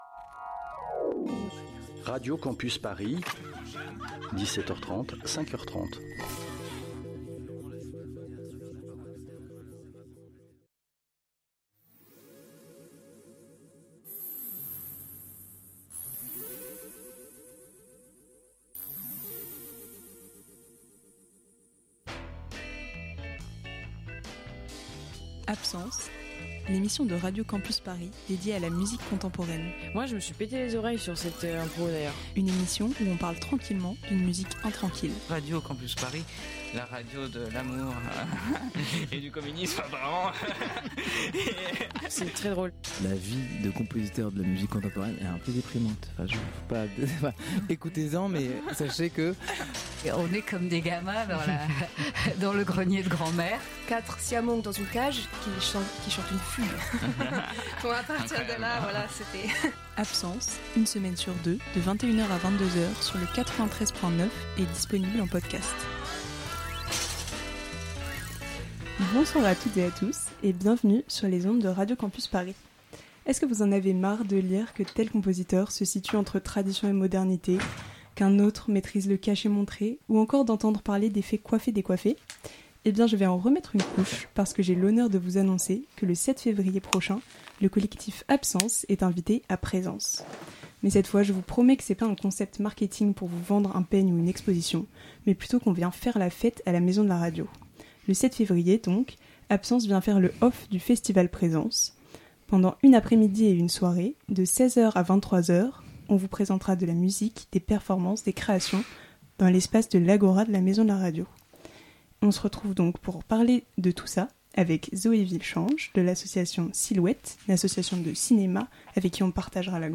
Absence ! - 12/01/2026 - Spéciale Agoraphone, le Off du festival Présences Partager Type Musicale Courants Alternatifs Classique & jazz lundi 12 janvier 2026 Lire Pause Télécharger Emission spéciale Agoraphone, le festival Off de Présences !